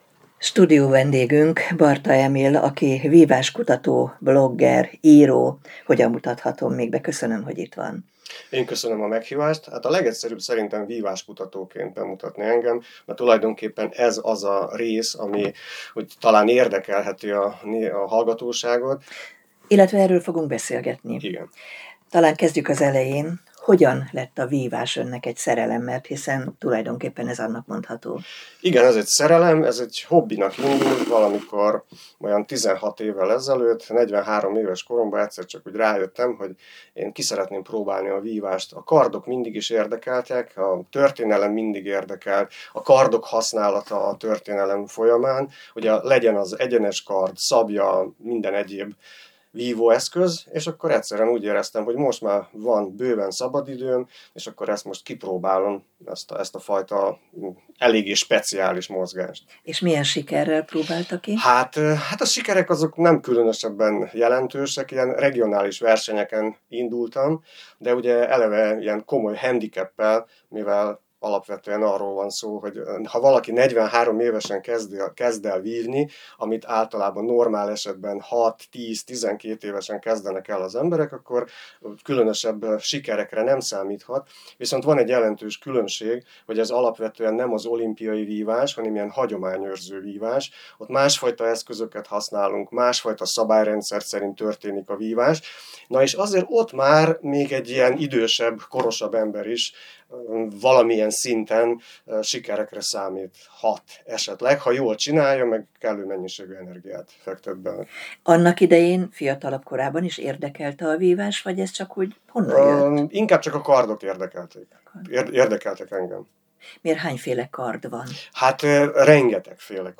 Stúdiónk vendége volt